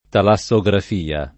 talassografia [ tala SS o g raf & a ] s. f.